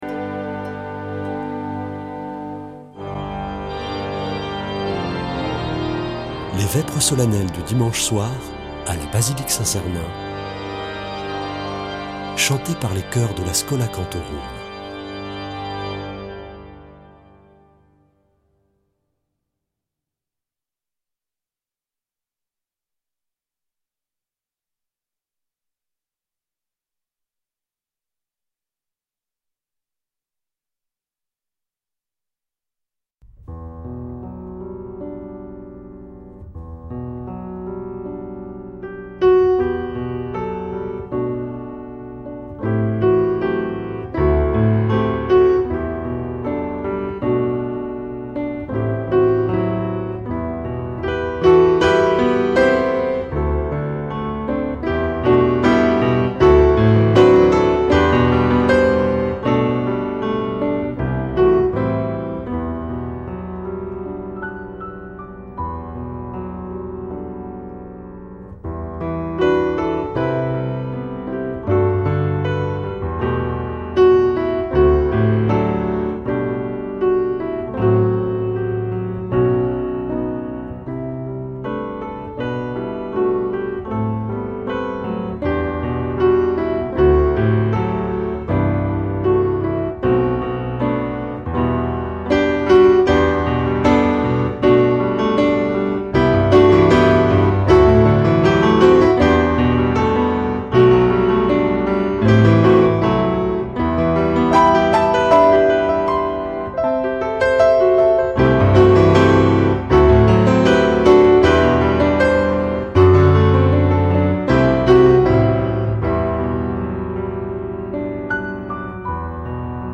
Vêpres de Saint Sernin du 31 mars
Une émission présentée par Schola Saint Sernin Chanteurs